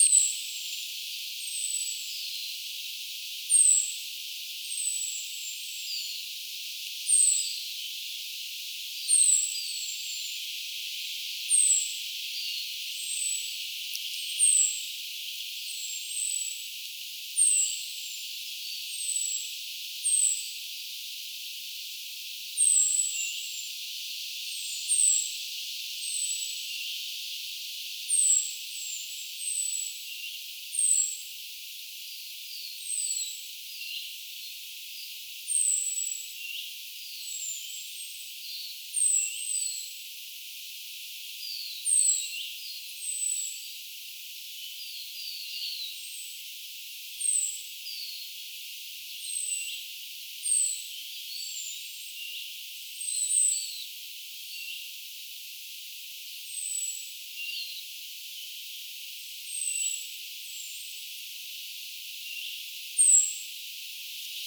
onko punarinnan lentopoikasten
huomioääntelyä?
mita_aania_ovatko_punarinnan_lentopoikasten_huomioaantelya_mita_aania.mp3